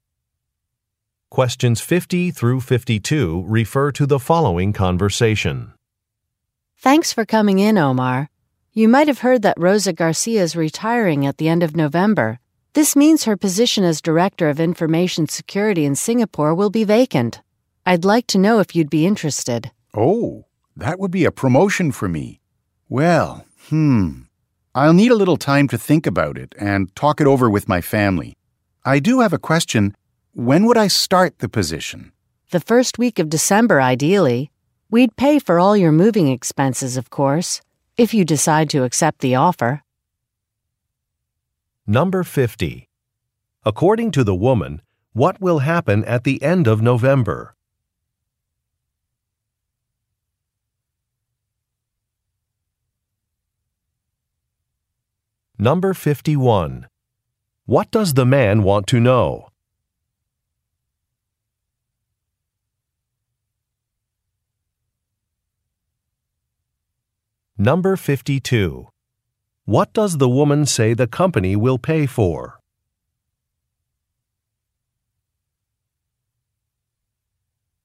Question 50 - 52 refer to following conversation:
50. According to the woman, what will happen at the end of November?